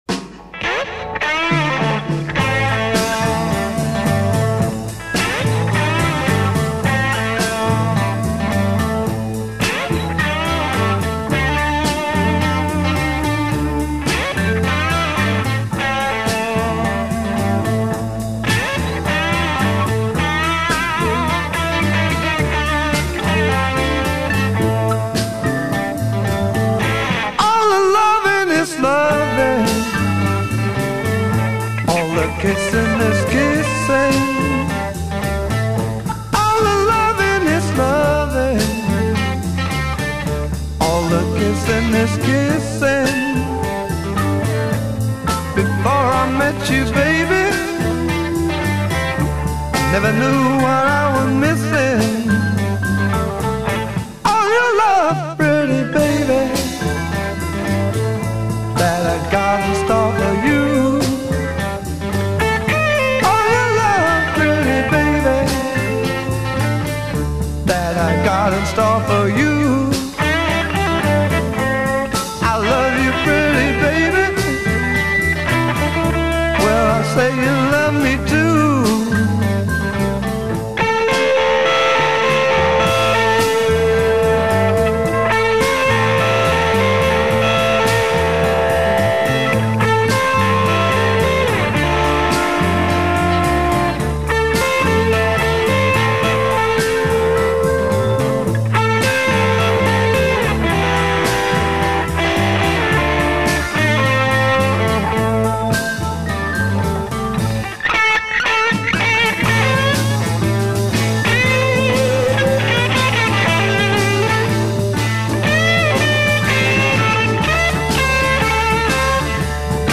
Verse 12 Guitar solo over backing track (note feedback) *
Verse 12 Guitar solo over double time backing track.
Verse 12 Solo voice over double-time backing track. c
Verse 12 Guitar solo (intro) at regular speed.